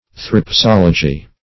Search Result for " threpsology" : The Collaborative International Dictionary of English v.0.48: Threpsology \Threp*sol"o*gy\, n. [Gr.